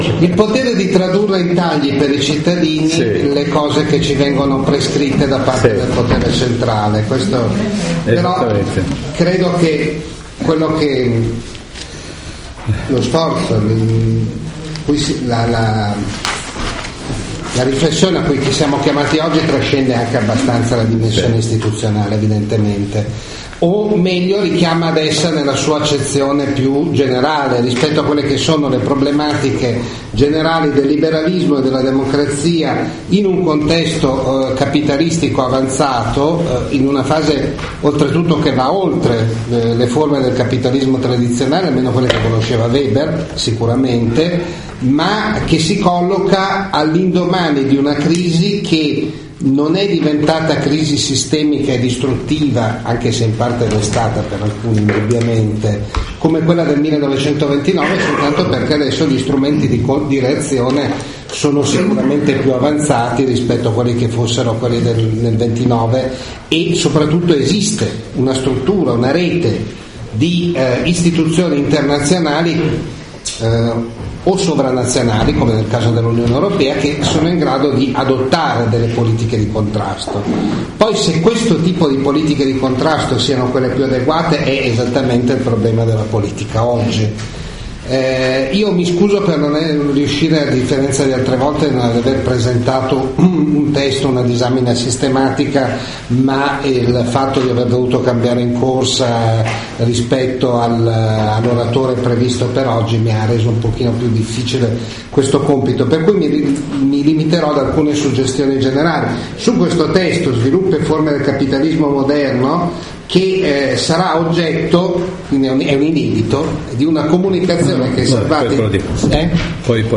Michele Salvati rilegge e analizza il lascito teorico di Max Weber nella lezione del Corso di Formazione di aprile 2015 dei Circoli Dossetti di Milano